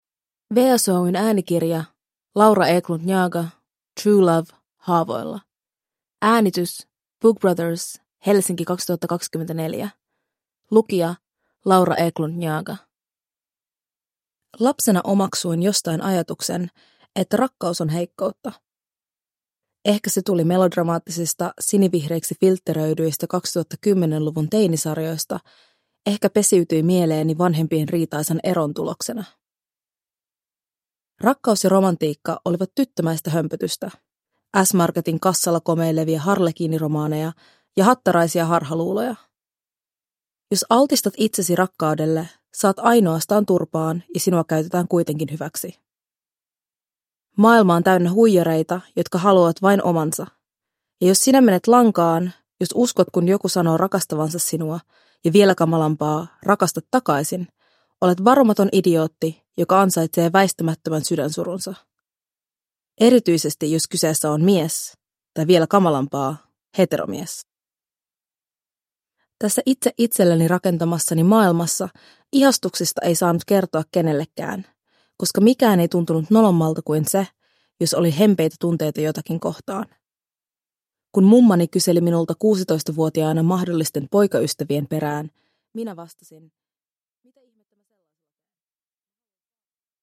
Toisella tuotantokaudella kuullaan monipuolisia kirjoittajia, jotka myös lukevat tekstinsä itse.
• Ljudbok